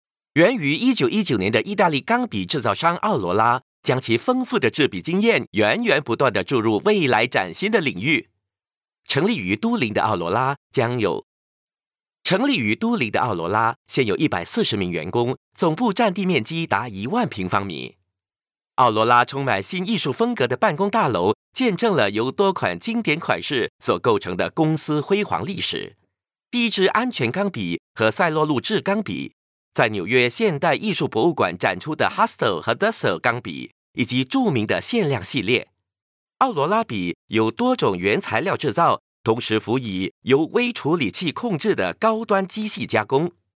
Professioneller Sprecher für Werbung, TV, Radio, Industriefilme und Podcasts aus China. Professional male voice over artist from China.
Sprechprobe: Werbung (Muttersprache):